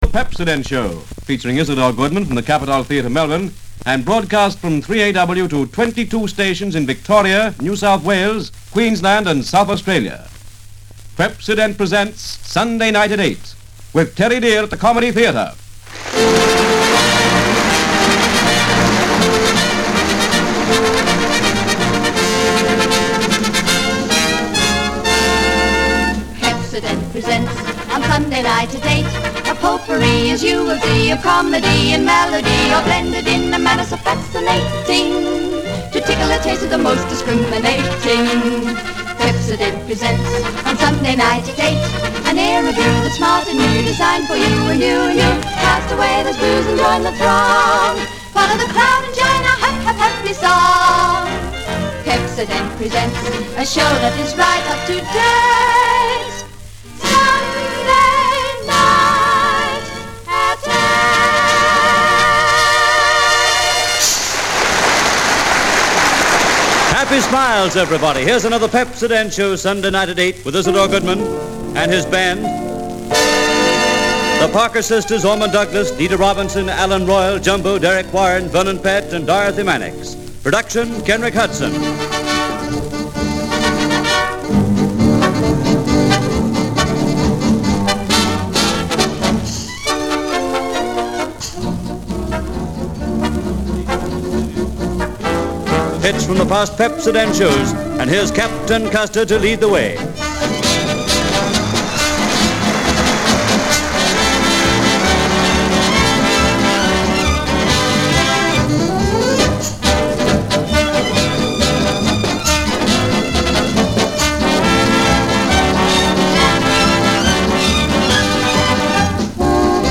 The Pepsodent Show, featuring Isador Goodman from the Capitol Theatre, Melbourne and broadcast from [1280kHz] 3AW Melbourne to 22 stations in Victoria, New South Wales, Queensland and South Australia.